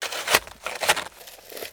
dig1.wav